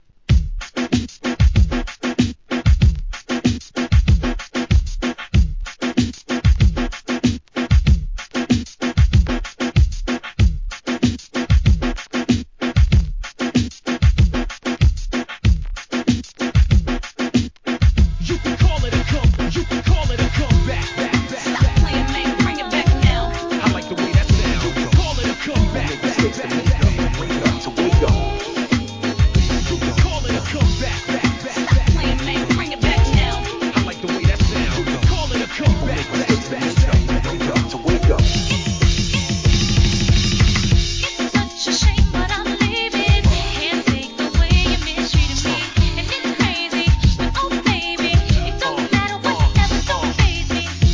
HIP HOP/R&B
使いやすく、さらにフロア仕様にリミックスした大人気のシリーズ第11弾!!